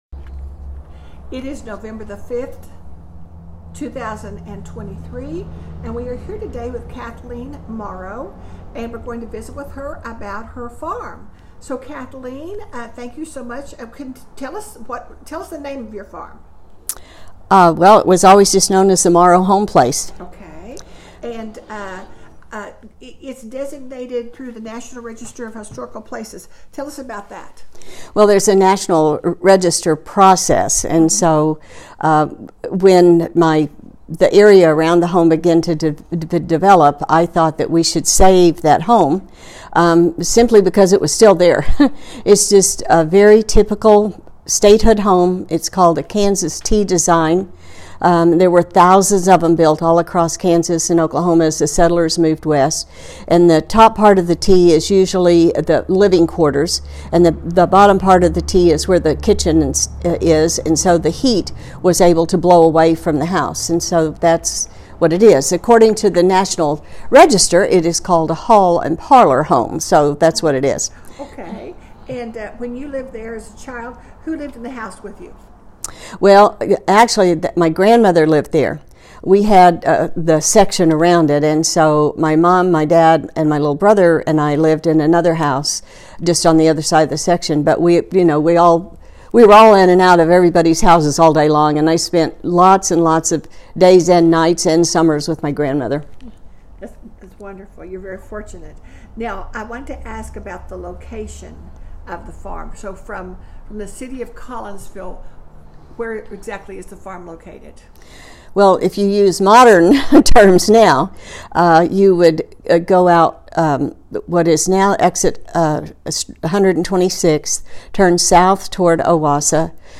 2023 Interview